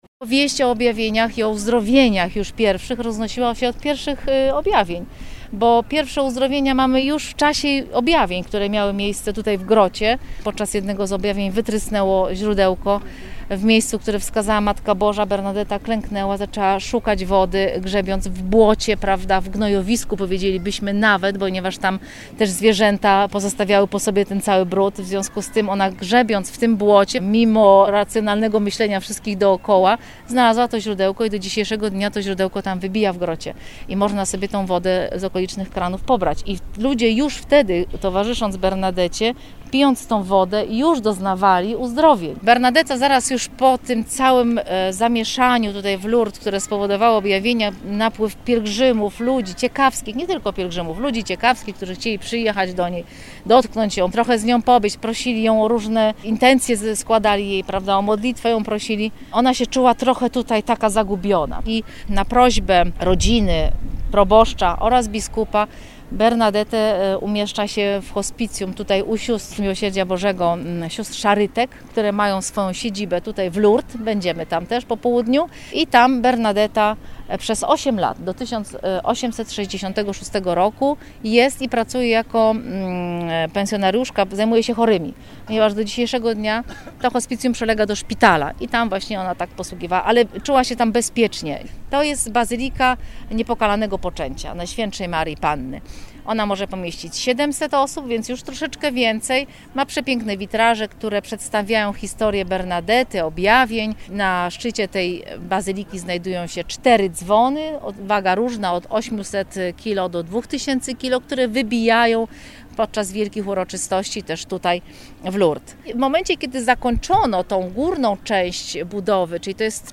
Przypominamy migawkę z pobytu w Lourdes razem z grupą pielgrzymów z parafii z Wójcic, którą odbyliśmy we wrześniu ub. roku.